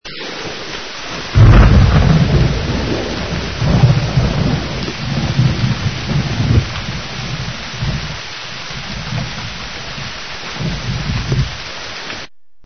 LLUVIA EFECTO DE SONIDO DE LLUVIA
Ambient sound effects
lluvia__efecto_de_sonido_de_lluvia.mp3